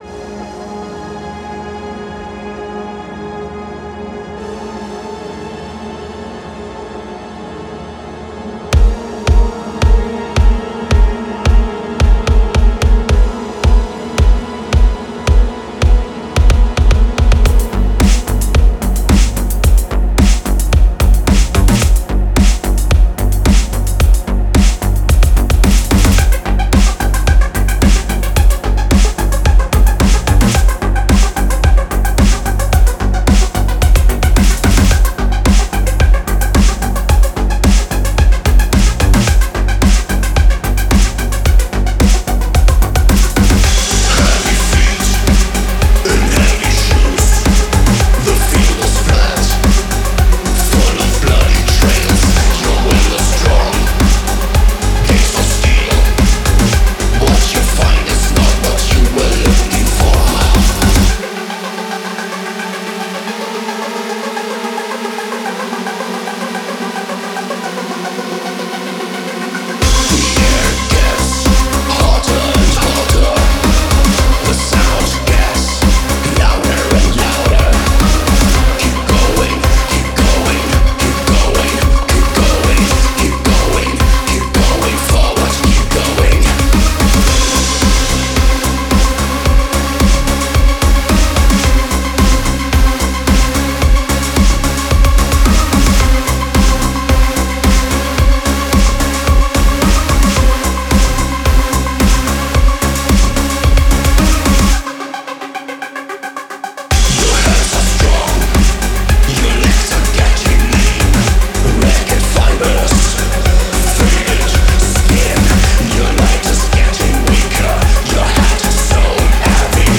EBM, Industrial, Dark Ambient, Rhythmic Noise